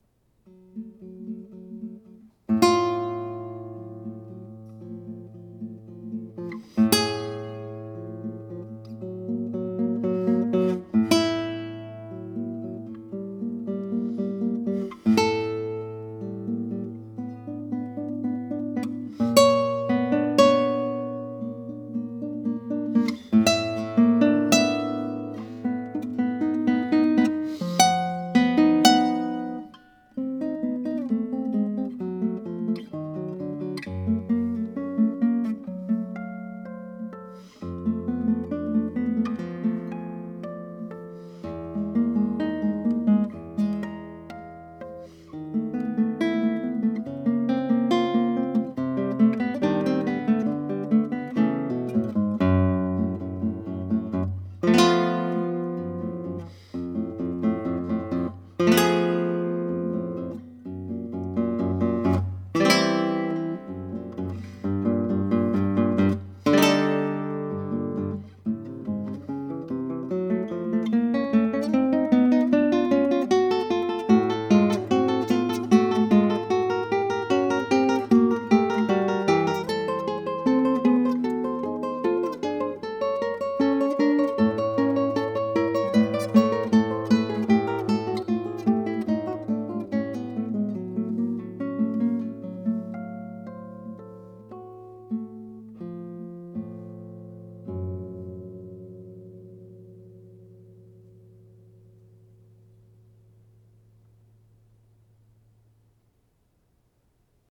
21 short etude-like movements, total length ca. 27 minutes, written in 2013.